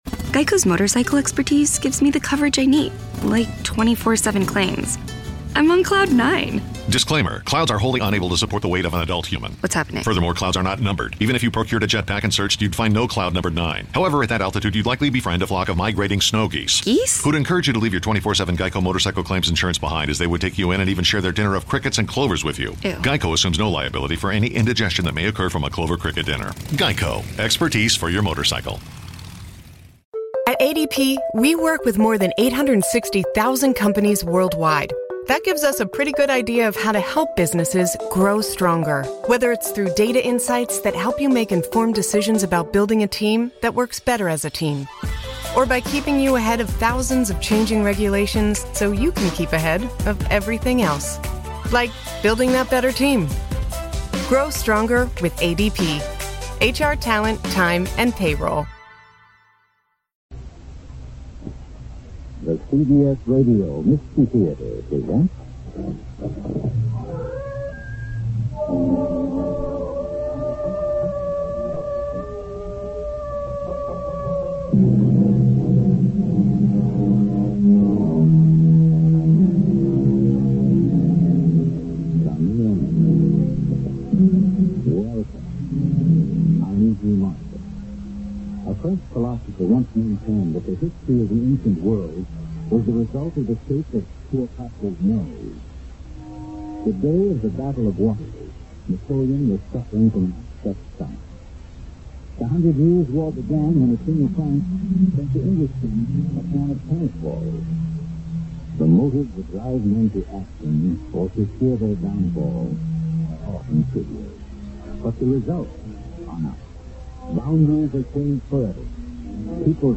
CBS Radio Mystery Theater (a.k.a. Radio Mystery Theater and Mystery Theater, sometimes abbreviated as CBSRMT) was a radio drama series created by Himan Brown that was broadcast on CBS Radio Network affiliates from 1974 to 1982